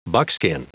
Prononciation du mot buckskin en anglais (fichier audio)
Prononciation du mot : buckskin